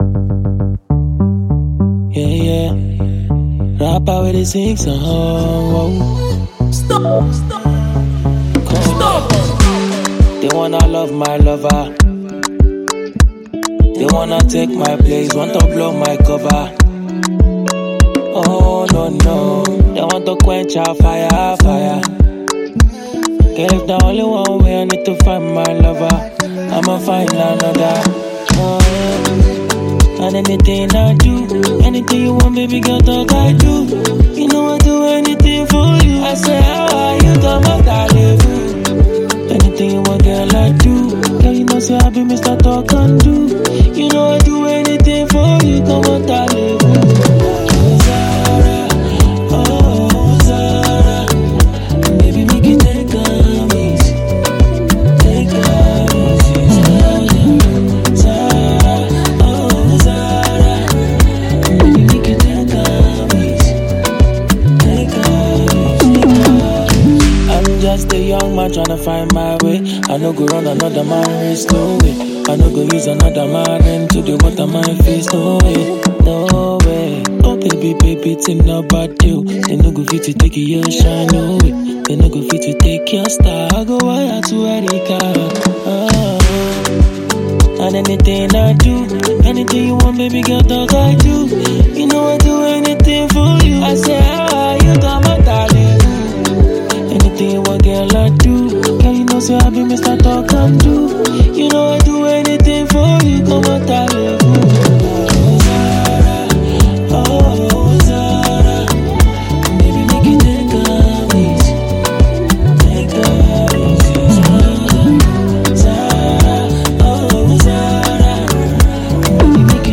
The rapper switch into a singer for his latest record.